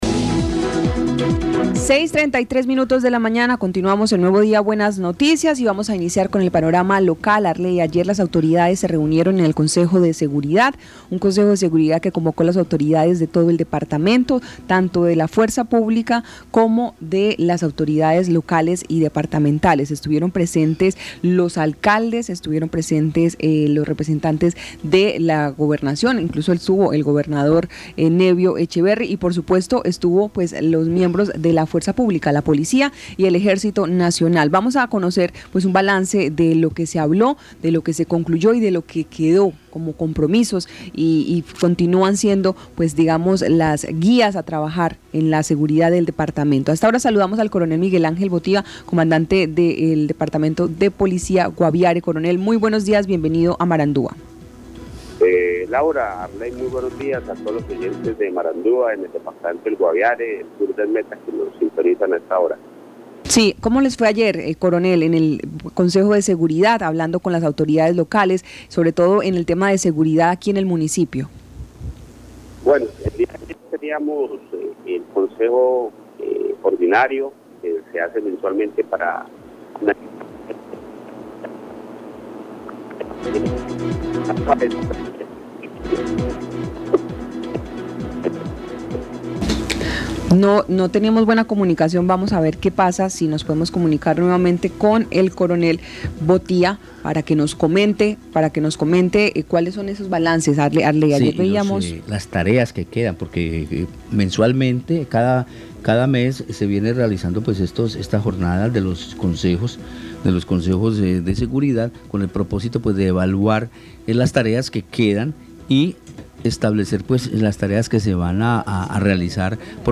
Escuche al Coronel Miguel Ángel Botía, comandante de Policía Guaviare.